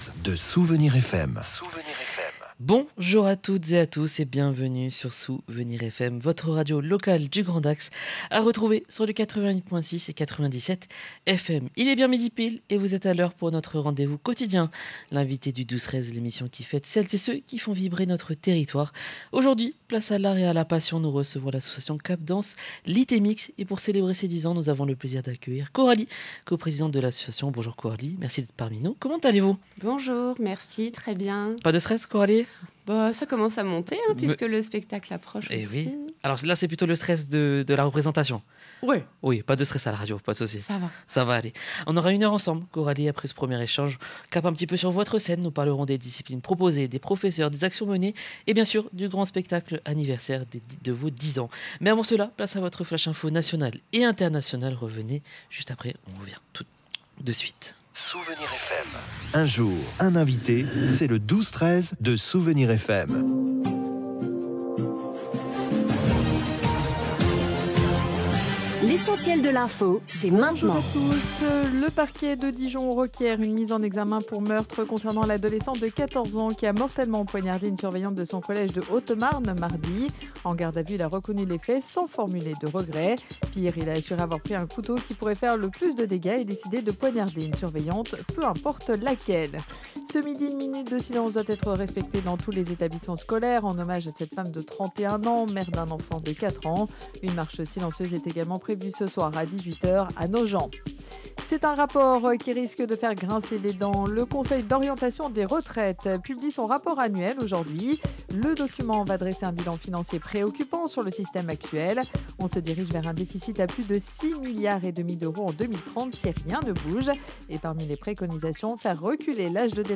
Ce midi, SOUVENIRS FM recevait l’association Cap’Danse Lit‑et‑Mixe